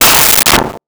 Metal Strike 03
Metal Strike 03.wav